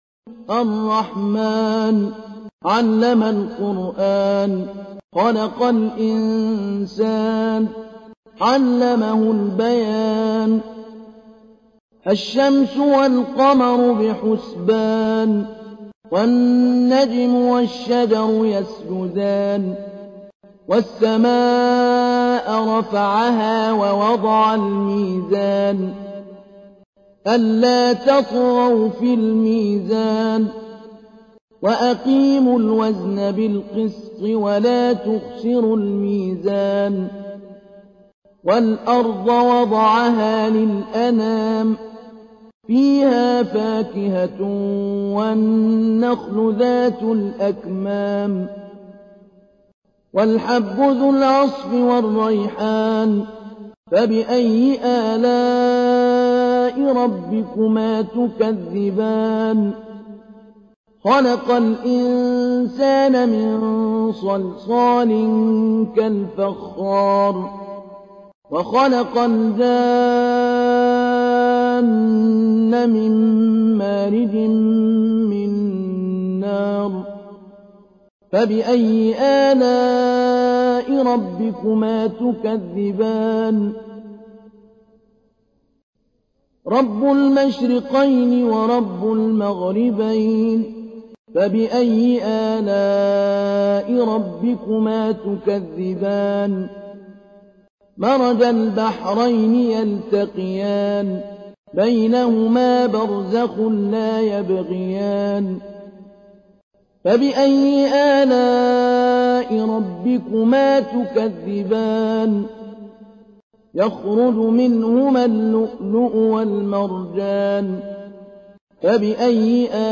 تحميل : 55. سورة الرحمن / القارئ محمود علي البنا / القرآن الكريم / موقع يا حسين